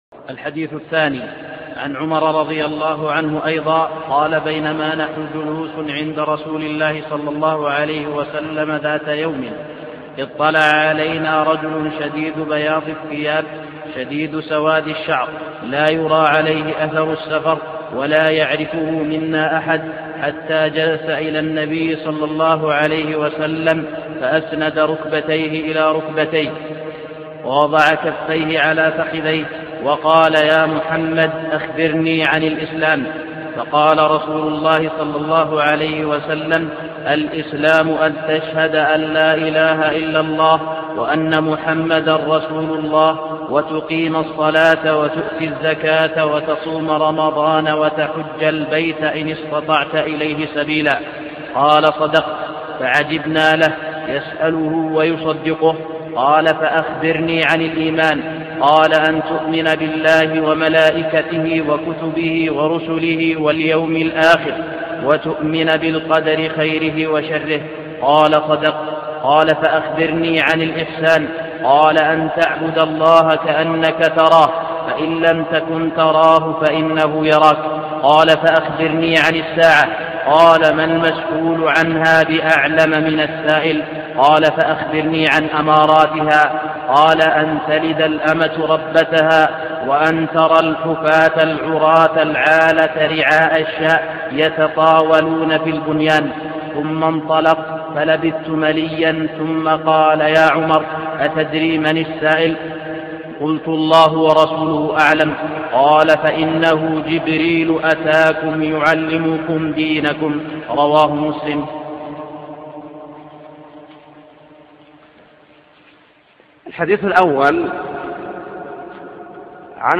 شرح الشيخ : صالح اللحيدان